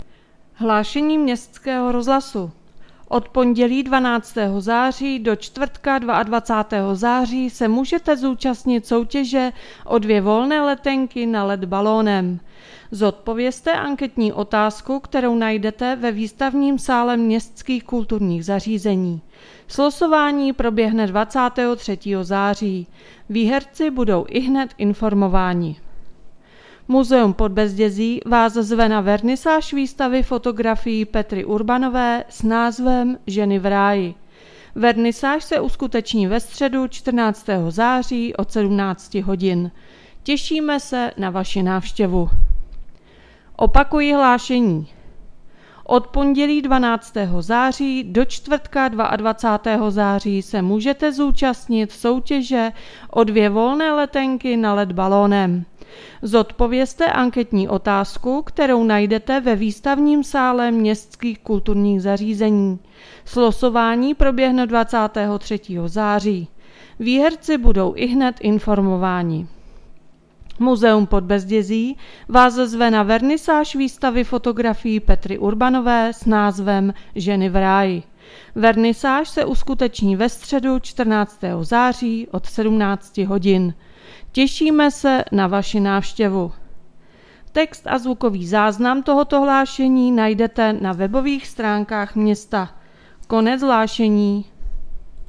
Hlášení městského rozhlasu 12.9.2022